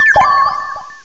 cry_not_phione.aif